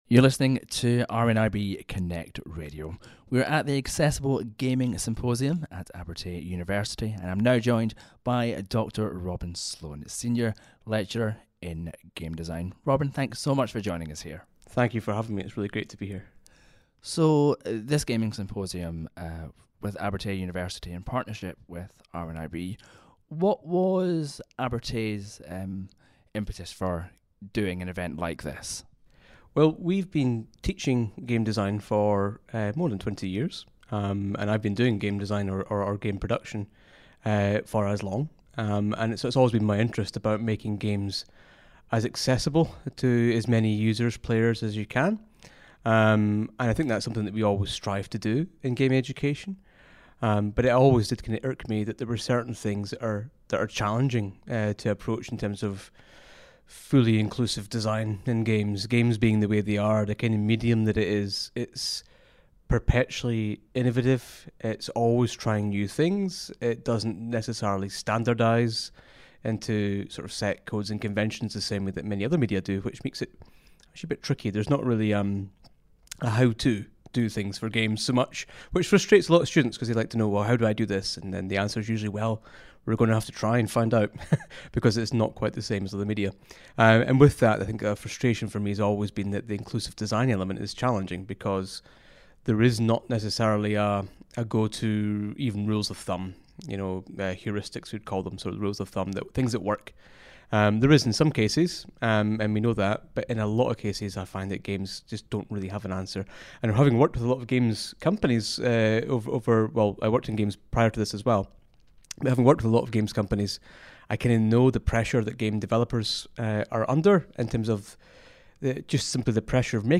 Accessible Gaming Symposium 2022 Interview